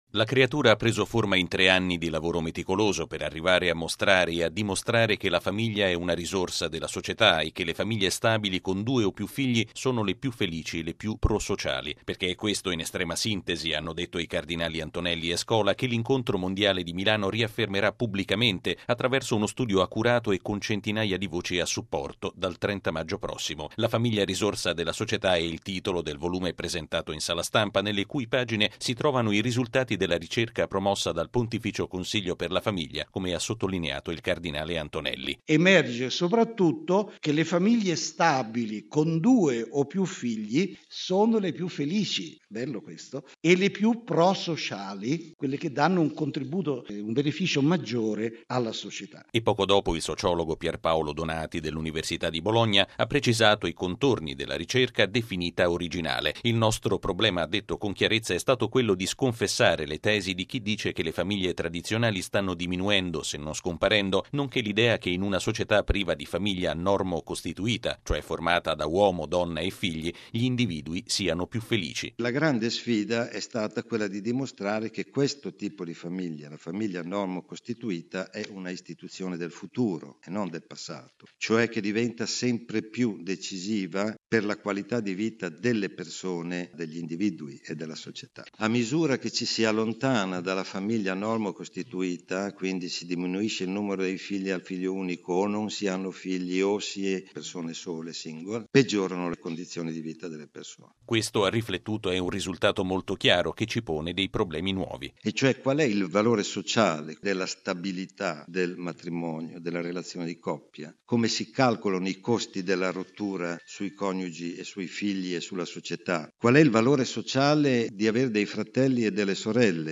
◊   La famiglia è una grande risorsa della società e un luogo dove si costruisce la felicità dei rispettivi membri, molto più che in altre tipologie di convivenza. È quanto è stato affermato questa mattina, in Sala Stampa Vaticana, durante la presentazione dell’Incontro mondiale delle famiglie in programma a Milano dal 30 maggio al 3 giugno prossimi.